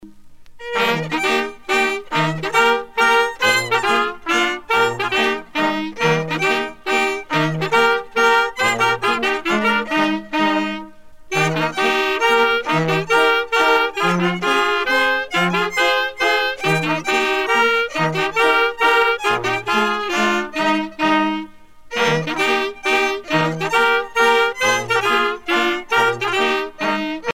danse : mazurka
groupe folklorique
Pièce musicale éditée